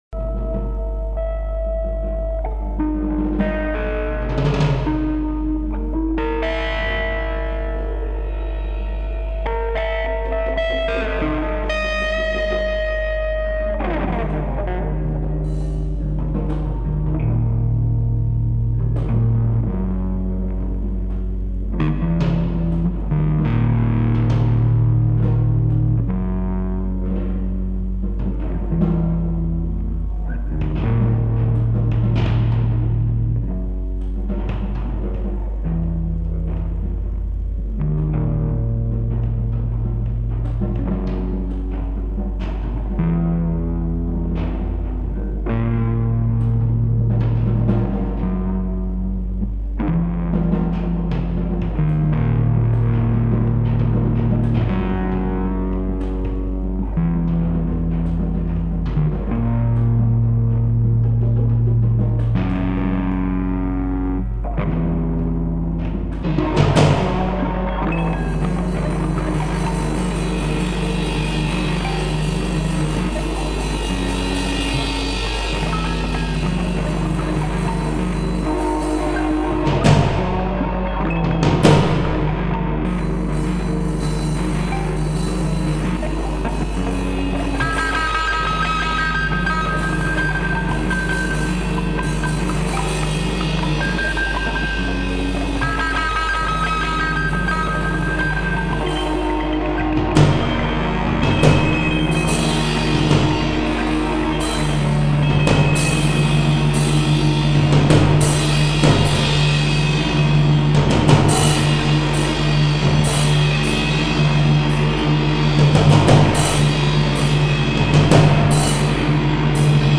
impro/avant-rock